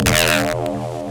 Hollow Distortion Bass.wav